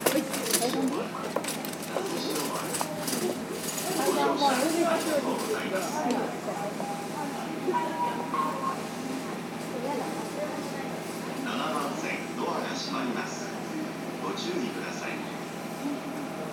那須塩原駅　Nasushiobara Station ◆スピーカー：ユニペックス小型,ユニペックス小丸型
7番線発車メロディー